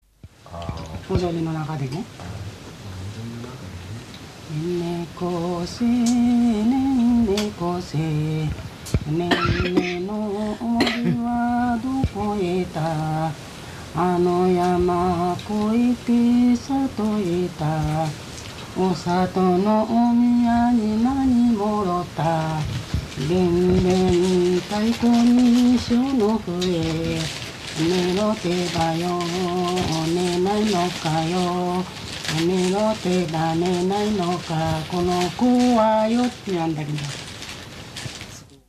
子守唄 子守歌